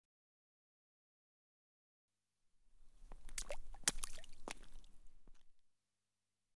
Звуки камней
Маленький камешек упал в воду